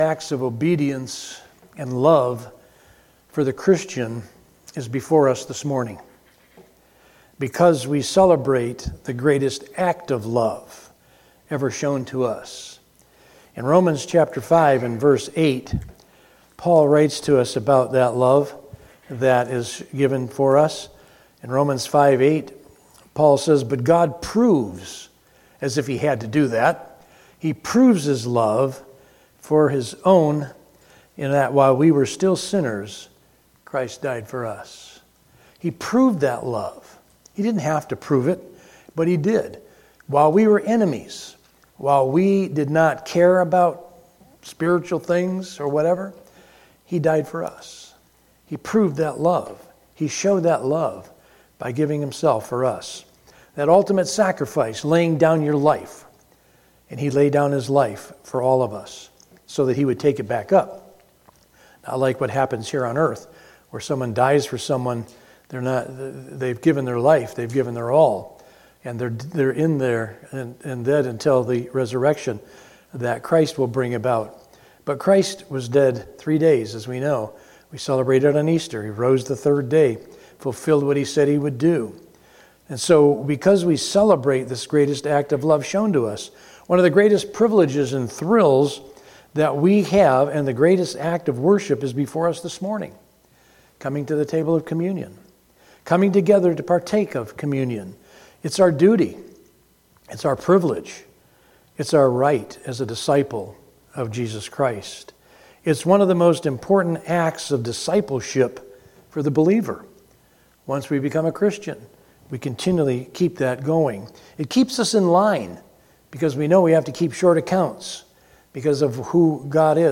From Series: "Sunday Morning - 11:00"
Related Topics: Sermon